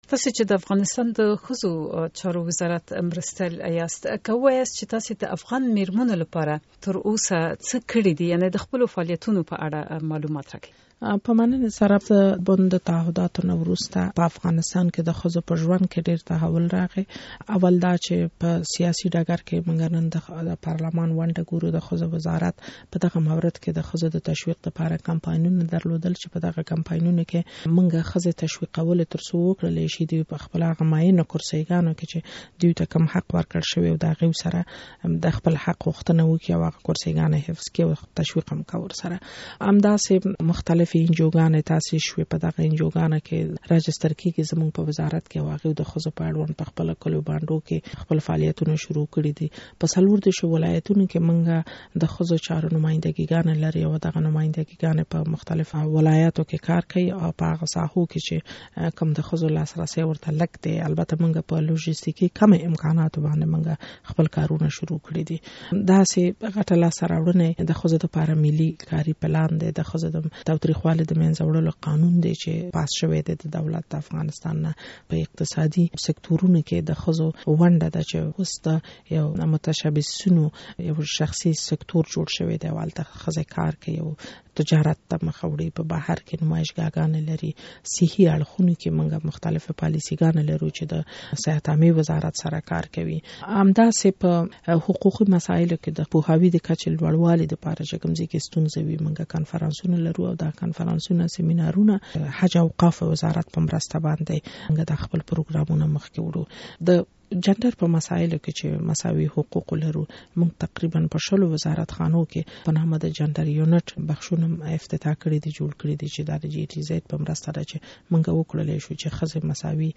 د ښځو چارو وزارت مرستيال پلوشه کاکړ شهيد سره مرکه